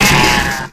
Audio / SE / Cries / CRANIDOS.ogg